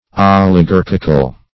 Oligarchic \Ol`i*gar"chic\, Oligarchical \Ol`i*gar"chic*al\, a.